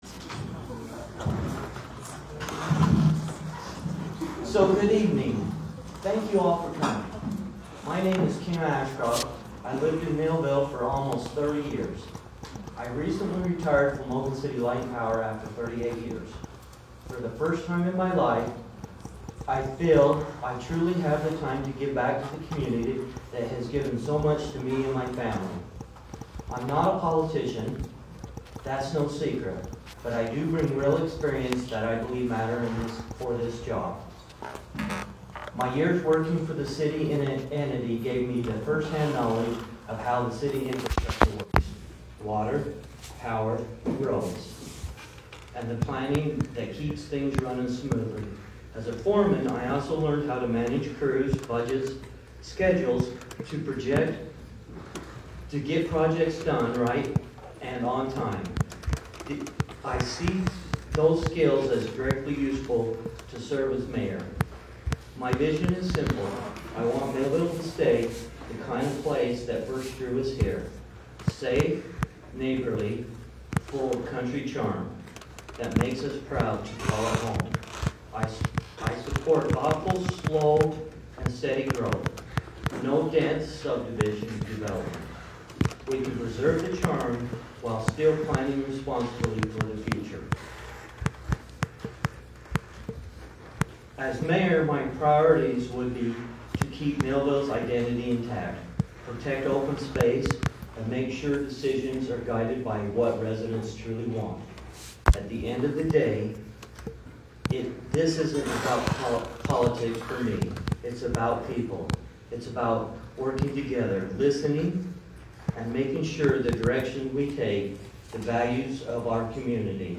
Listen to audio from Candidates Meeting
candidates-meeting.m4a